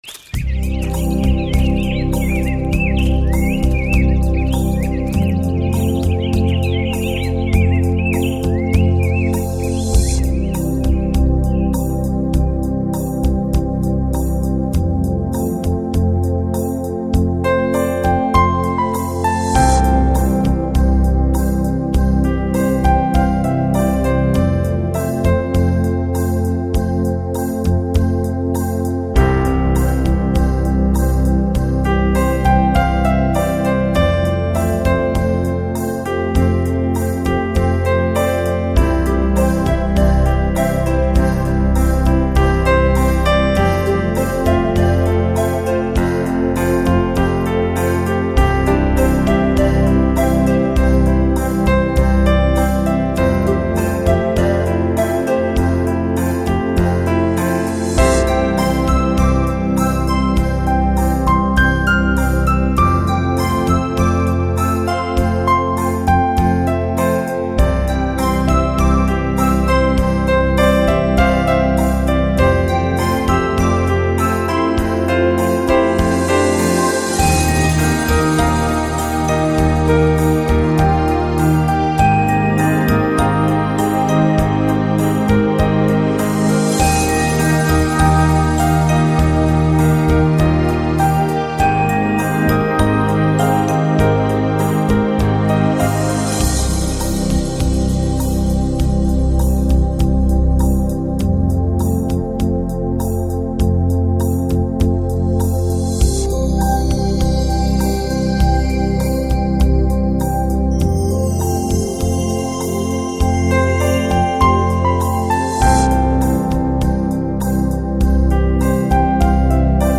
专辑歌手：纯音乐